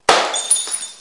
破瓶2
描述：瓶子被扔到砖墙上，砸到地上
标签： 下降 打破
声道立体声